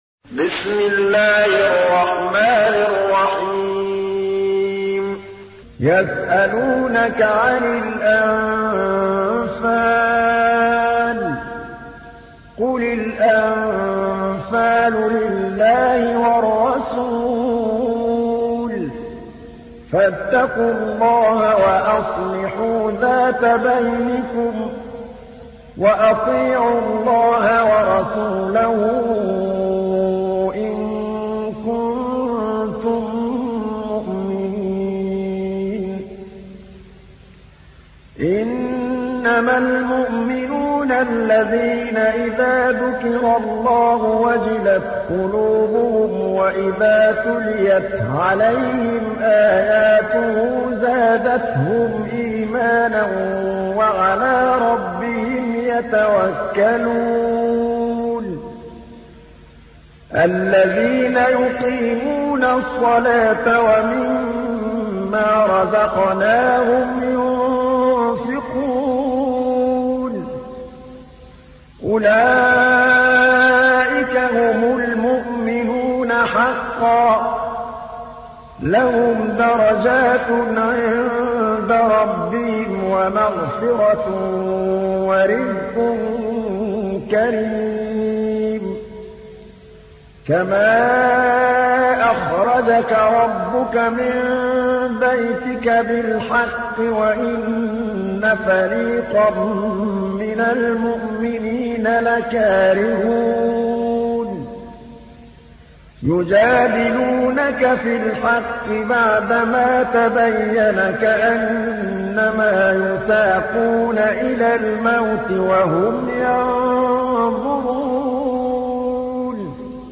تحميل : 8. سورة الأنفال / القارئ محمود الطبلاوي / القرآن الكريم / موقع يا حسين